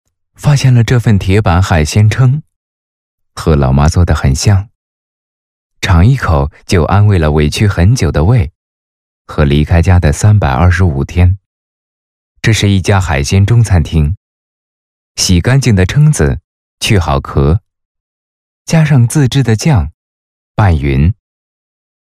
娓娓道来 舌尖美食
年轻磁性男音，自然。